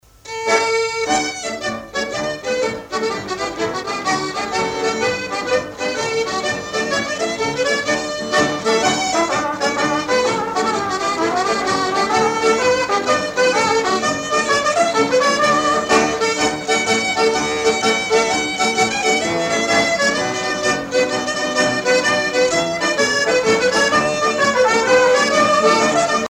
danse : square dance
Pièce musicale éditée